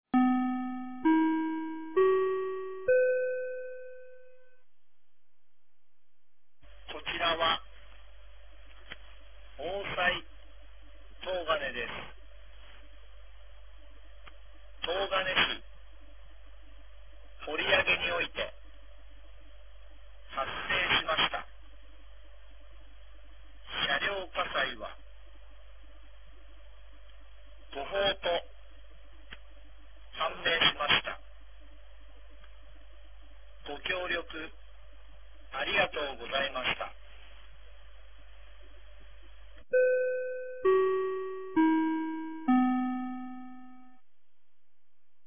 2025年02月08日 18時59分に、東金市より防災行政無線の放送を行いました。